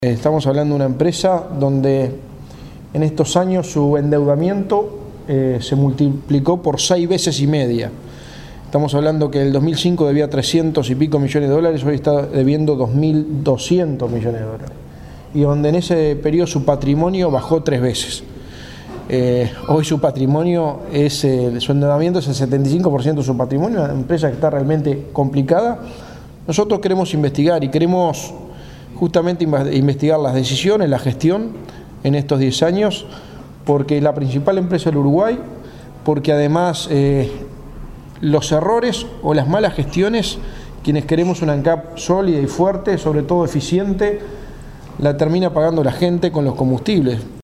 El Partido Nacional presentó formalmente la solicitud para conformar una comisión pre investigadora para ANCAP, según confirmó a 810 Vivo el senador Álvaro Delgado.